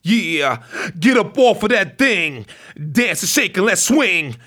RAPHRASE08.wav